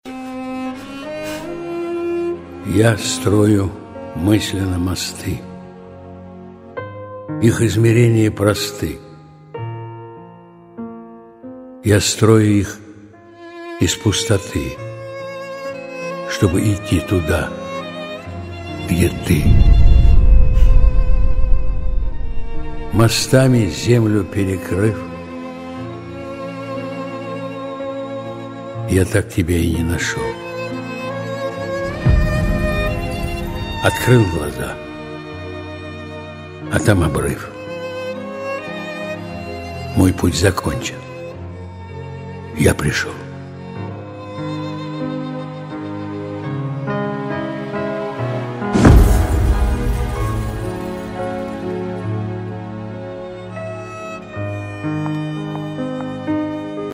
Несколько стихотворений в исполнении автора: